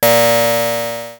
Ringing08.wav